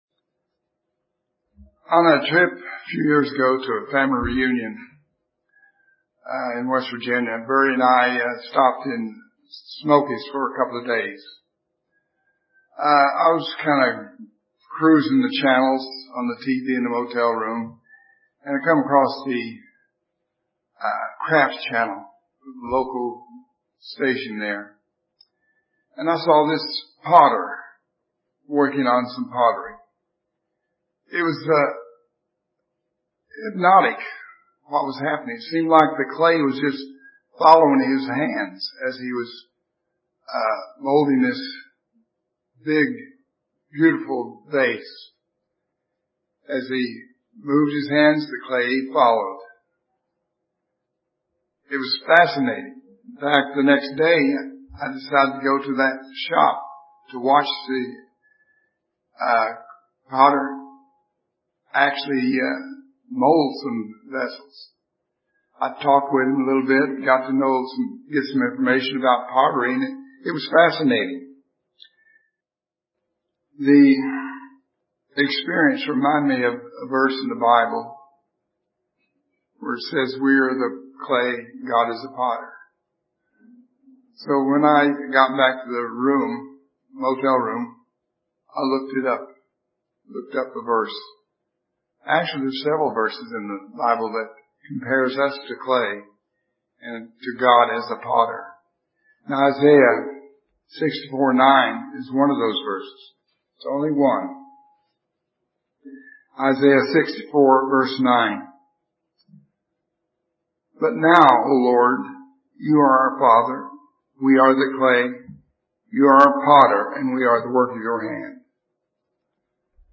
This sermon examines the craft of pottery and looks at the lessons we can learn about ourself and how God is working with us in order to help us to understand why we may be having trials and the benefit of enduring them.